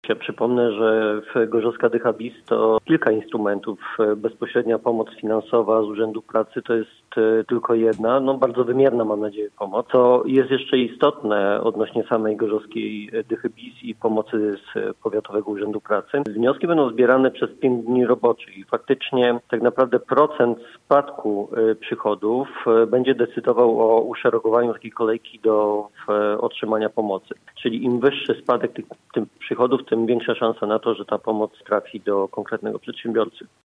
Mówi Jacek Szymankiewicz, wiceprezydent Gorzowa, poranny gość Radia Gorzów.